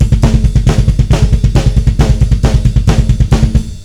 Black Metal Drum Rudiments
Beat 3 - The Bass Ruff
bassruf2.wav